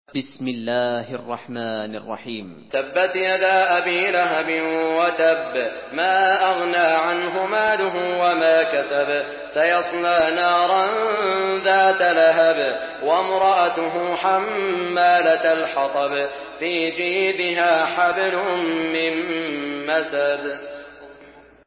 Surah আল-মাসাদ MP3 in the Voice of Saud Al Shuraim in Hafs Narration
Murattal